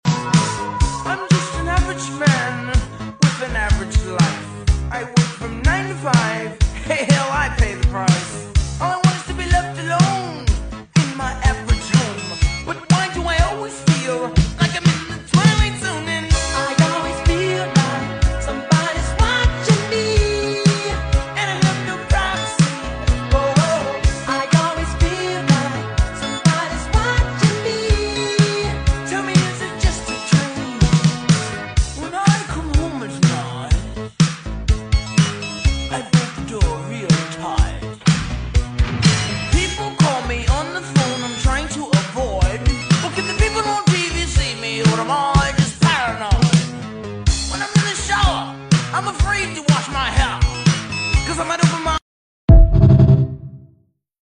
Taking the top off of log #2 for the day on this massive Black Walnut💪🏼